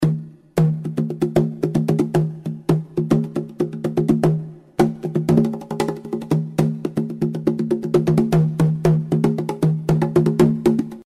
LapDrum Standard
• Studio-quality hand-drums are great for indoor and outdoor use
• Made of dark maple and trimmed with walnut
5216_SoundClip_LapDrumStandard.mp3